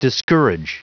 Prononciation du mot discourage en anglais (fichier audio)
Prononciation du mot : discourage